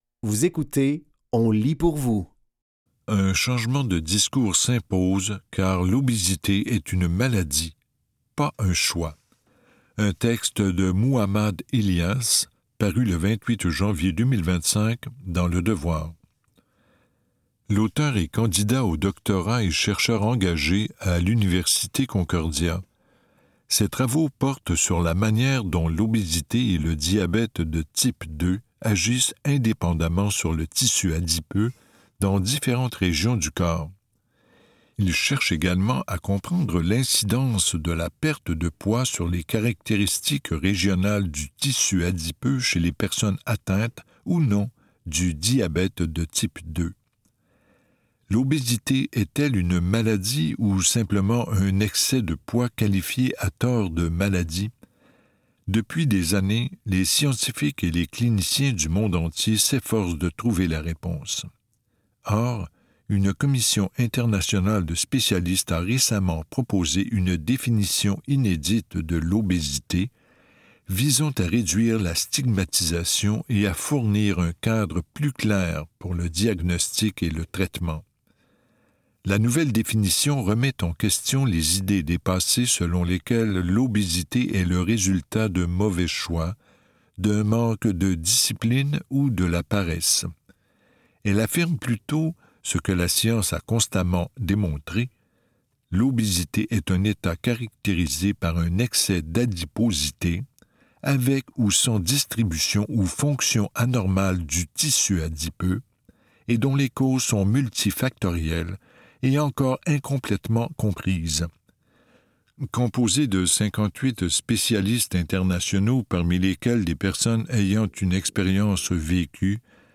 Dans cet épisode de On lit pour vous, nous vous offrons une sélection de textes tirés du média suivant : Le Devoir, La Presse et Fugues. Au programme: Un changement de discours s'impose, car l'obésité est une maladie, pas un choix, un texte de Muhammad Ilyas, paru le 28 janvier 2025 dans Le Devoir.